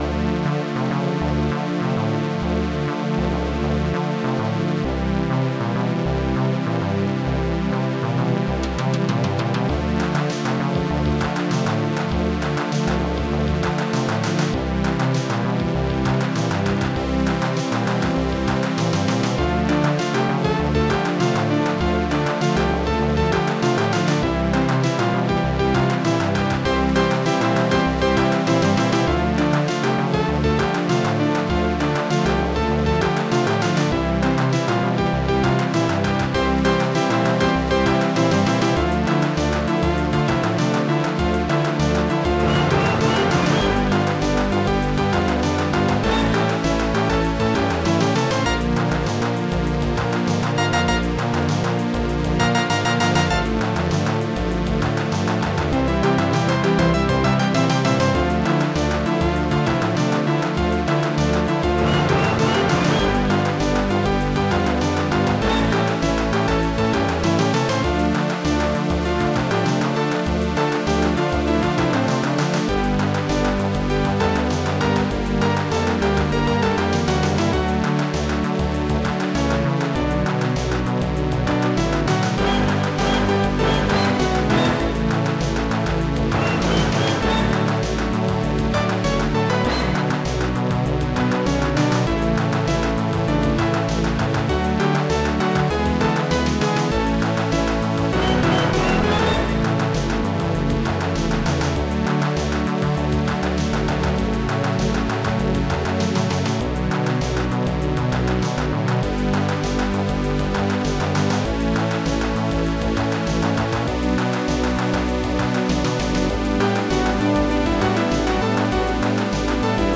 Synth
OctaMED Module